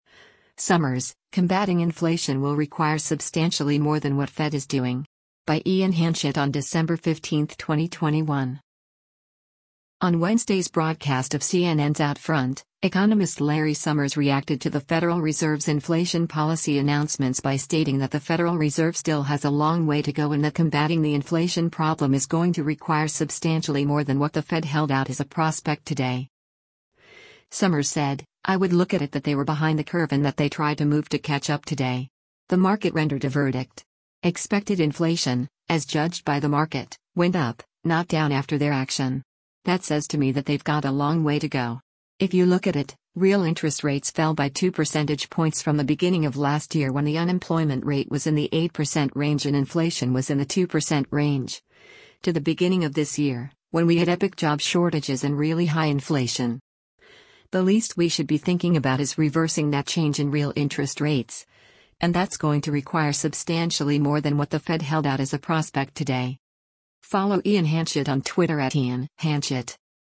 On Wednesday’s broadcast of CNN’s “OutFront,” economist Larry Summers reacted to the Federal Reserve’s inflation policy announcements by stating that the Federal Reserve still has “a long way to go” and that combatting the inflation problem is “going to require substantially more than what the Fed held out as a prospect today.”